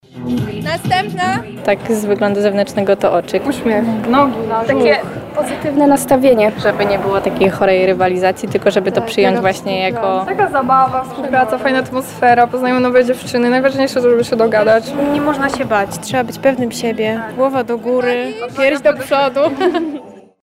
– Uśmiech, oczy, nogi, pozytywne nastawienie – o swoich atutach mówią kandydatki do tytułu Miss Lubelszczyzny.